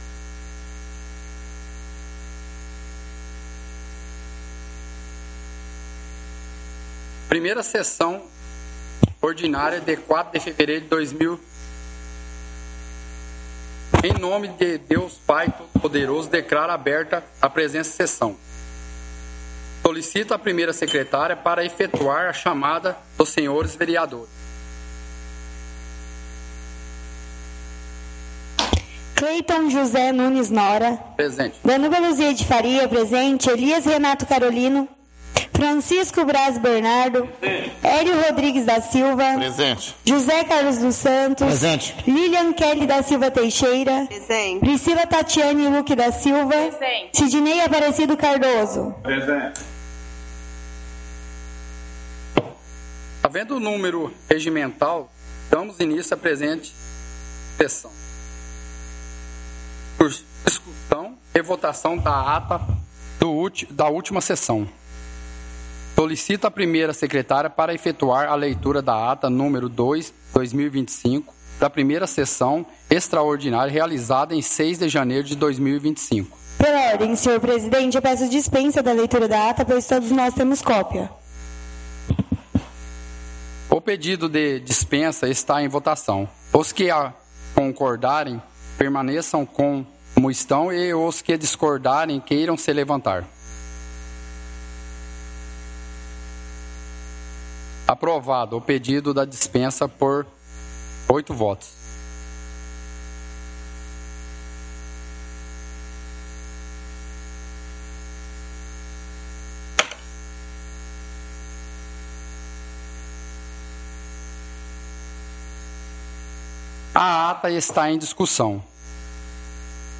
Áudio da 1ª Sessão Ordinária – 04/02/2025